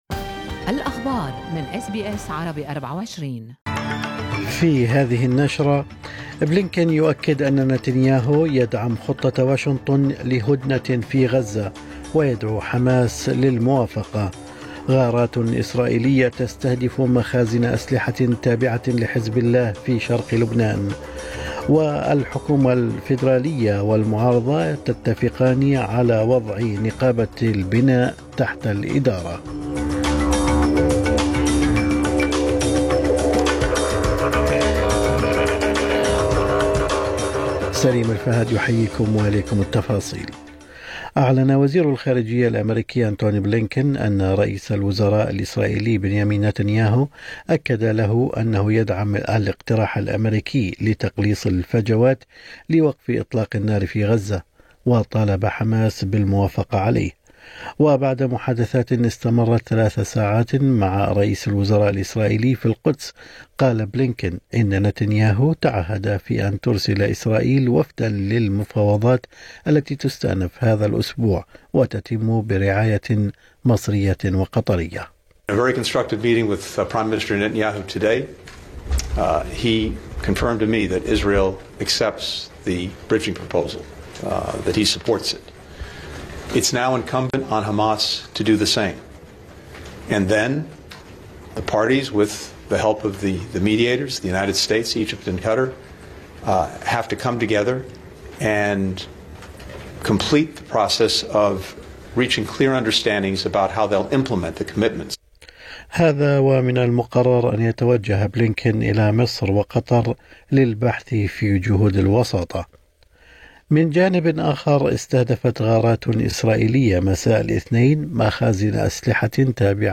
نشرة أخبار الصباح 20/8/2024